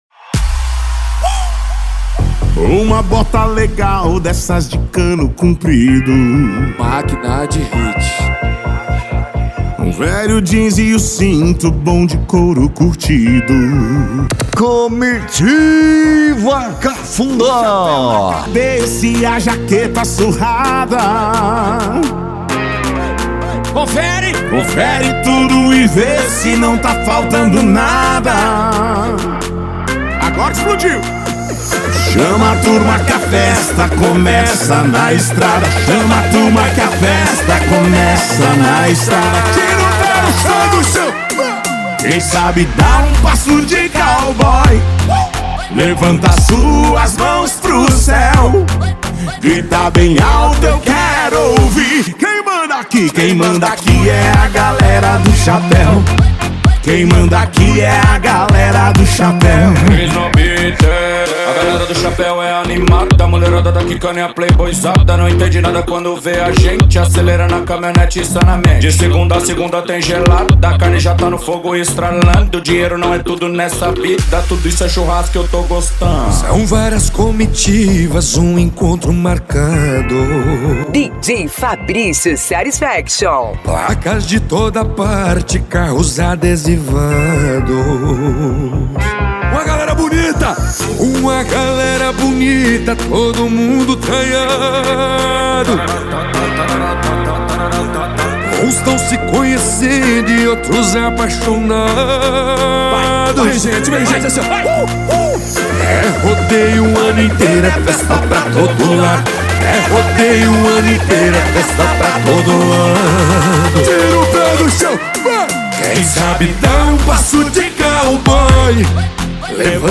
Country Music
Funk
Funk Nejo
SERTANEJO